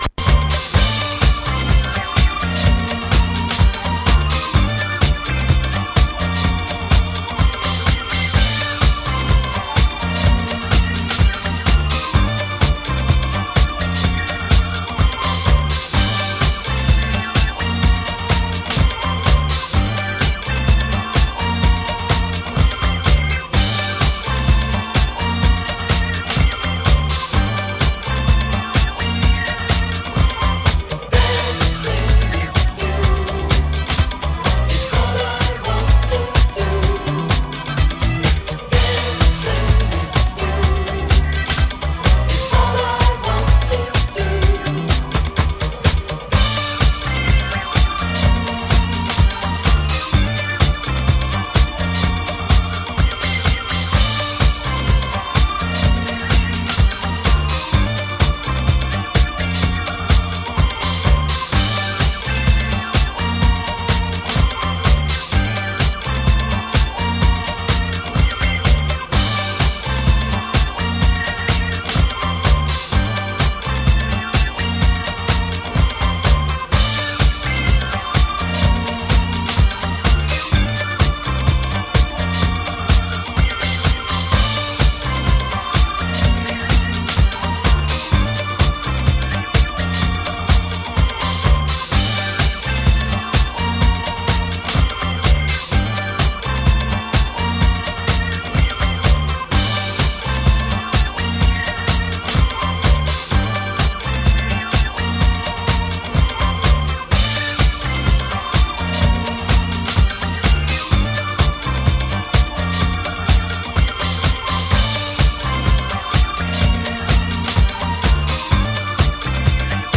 タフに華麗に。